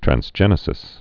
(trăns-jĕnĭ-sĭs, trănz-)